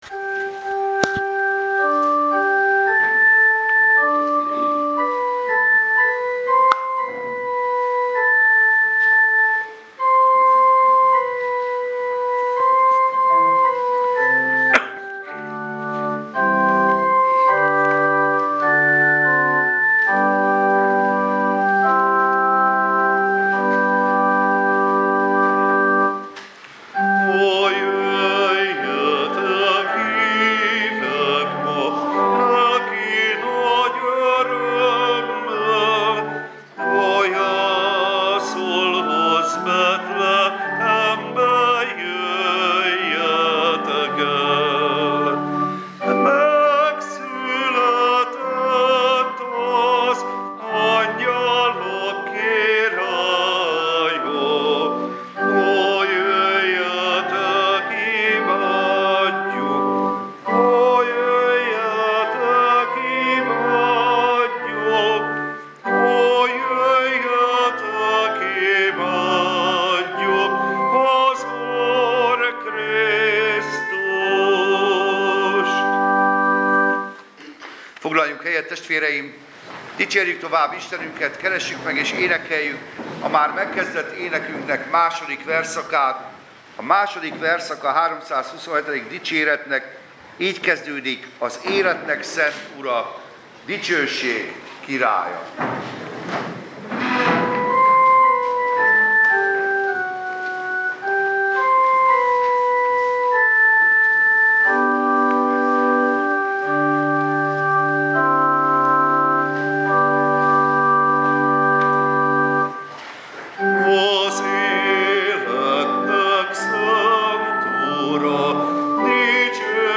Szentestei istentisztelet
Service Type: Igehirdetés